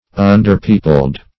Underpeopled \Un`der*peo"pled\, a.